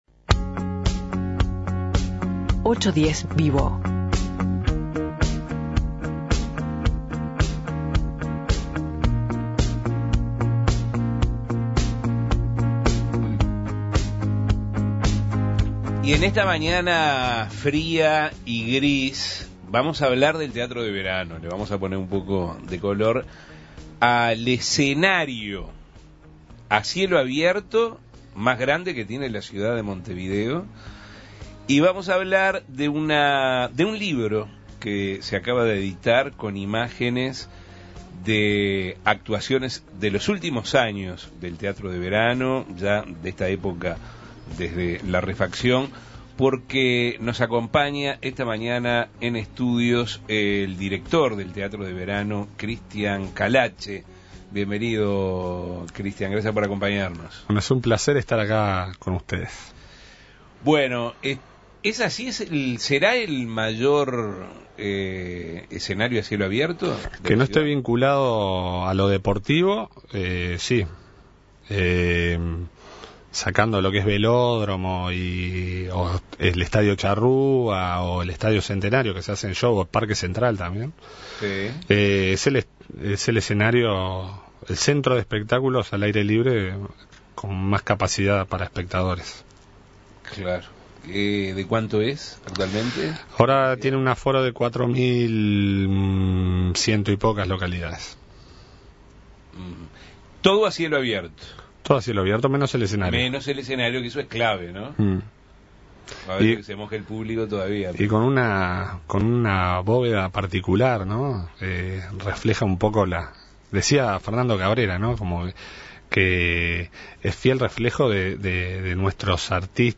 El Teatro de Verano Ramón Collazo está por cumplir sus 70 años y para conmemorarlo se publicó el libro "Teatro a cielo abierto", un trabajo conjunto entre la Intendencia de Montevideo y el centro de Fotografía de Montevideo. 810 VIVO Avances, Tendencia y Actualidad recibió en estudios